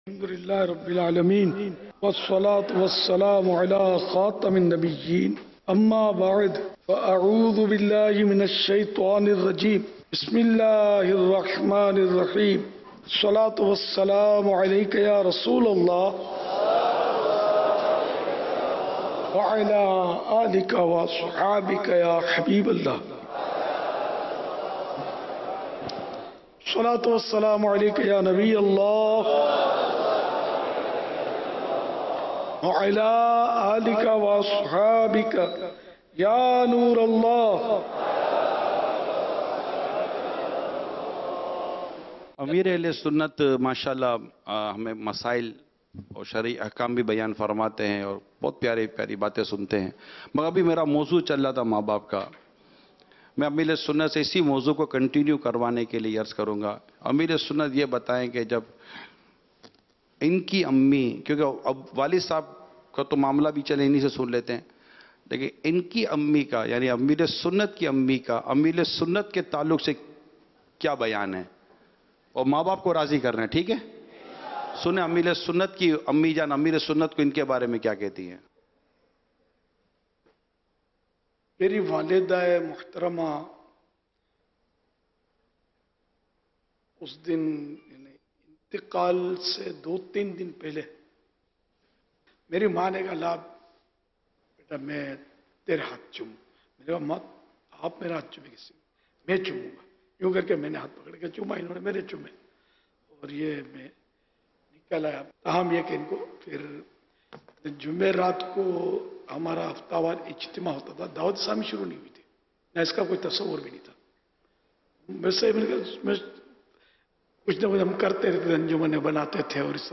Namaz e Fajr Ke Bad Ka Bayan